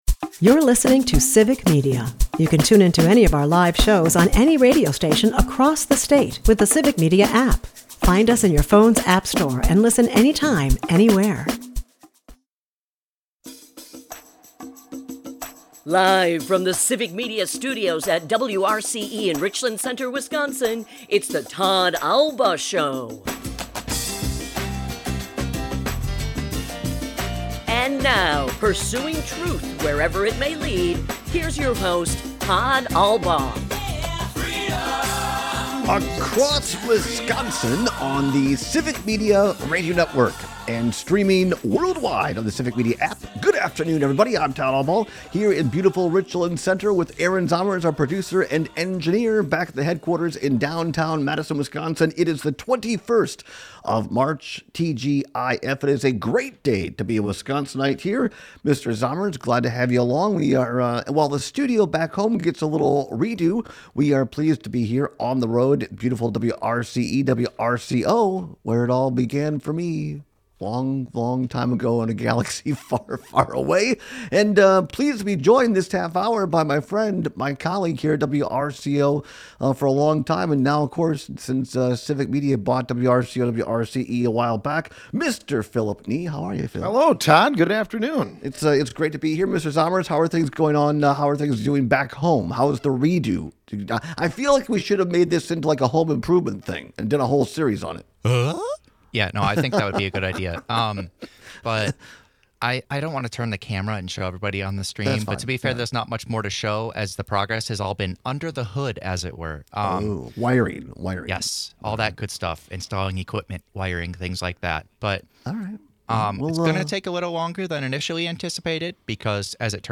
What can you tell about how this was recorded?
We take texts and calls on the best way to trap a hog.